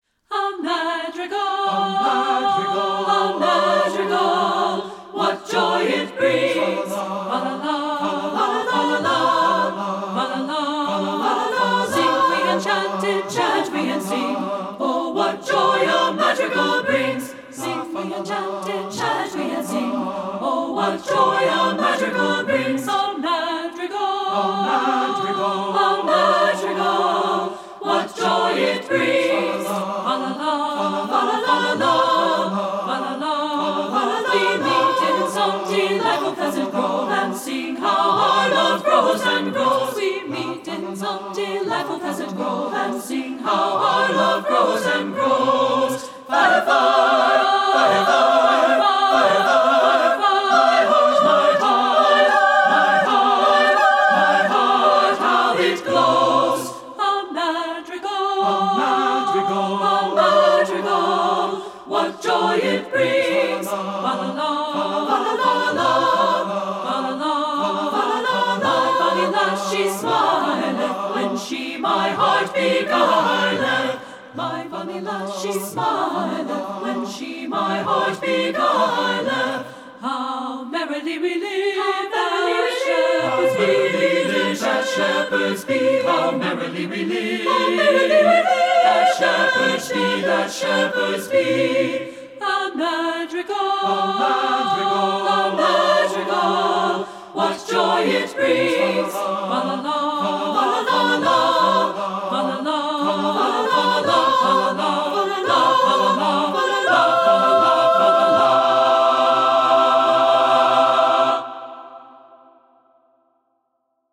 secular choral
SSA (3-part recording), sample